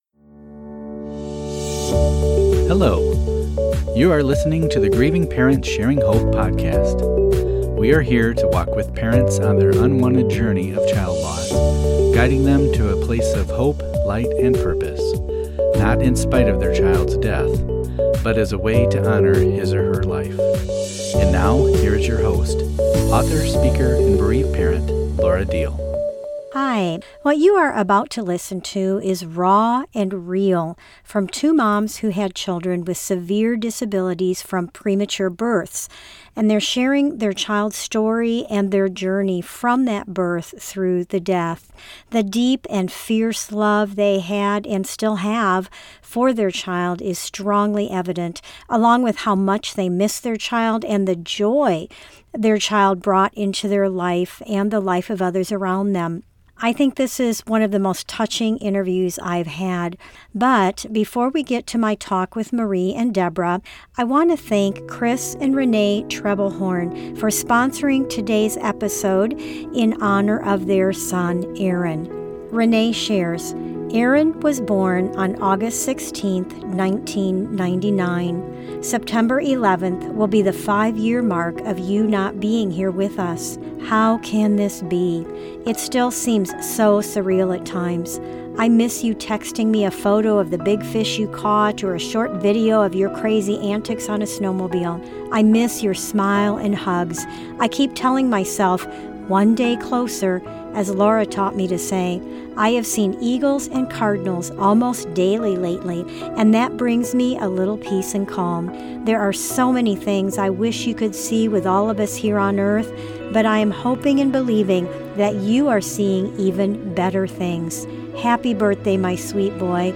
GPS Hope shares the raw stories of two moms losing a disabled child, offering hope, faith, and wisdom for grieving parents.